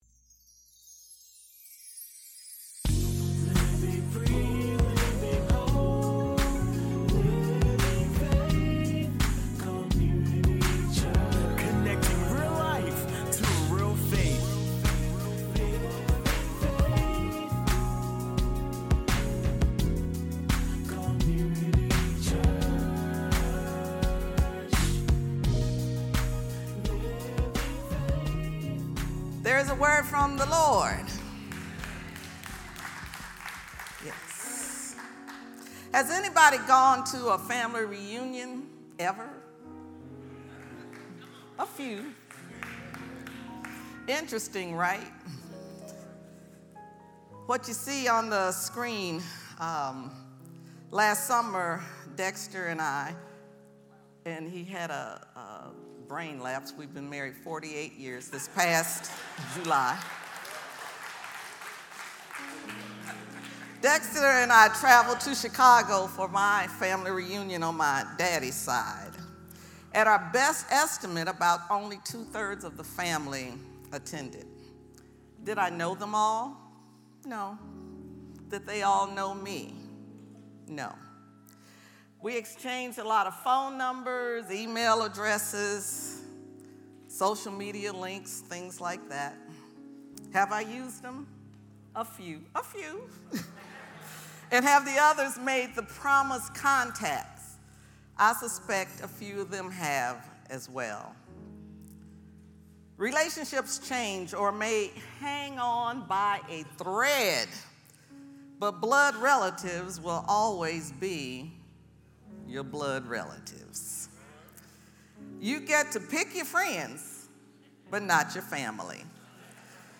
Audio Sermons | Living Faith Community Church